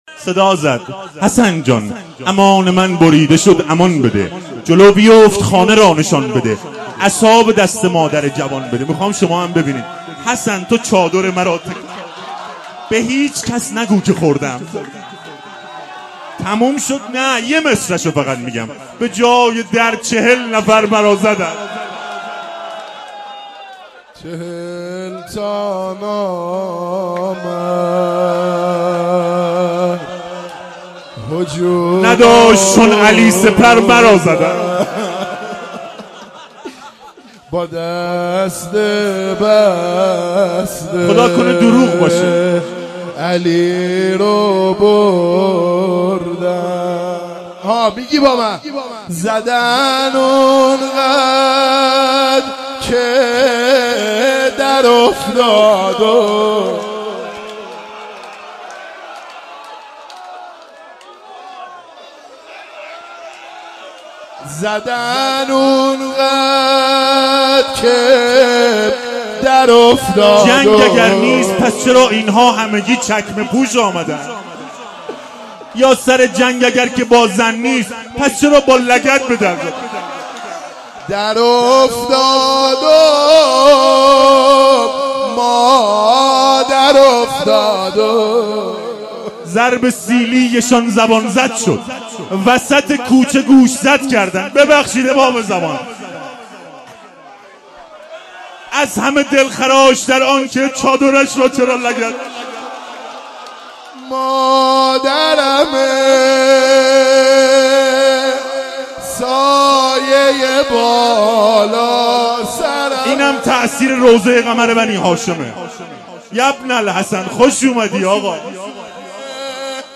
شعرخوانی و روضه(سنگین)